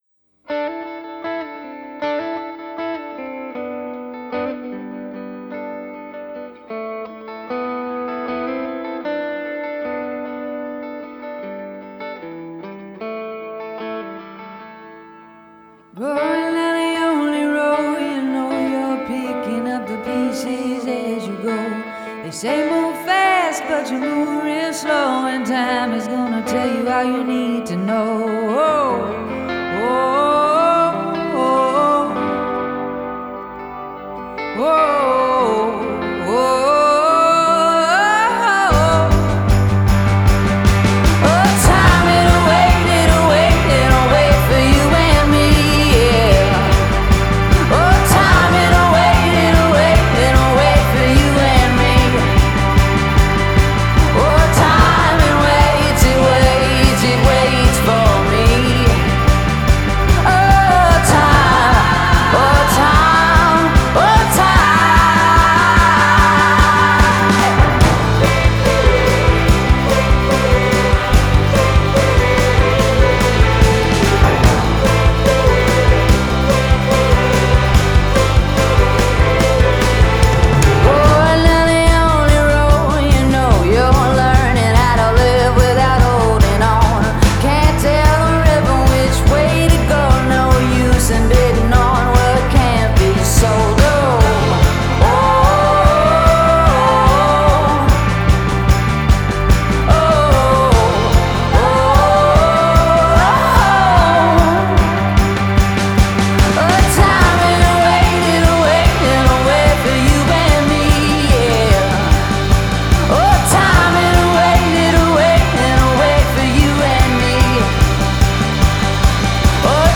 Genre: Indie Pop, Pop Rock,
Singer-Songwriter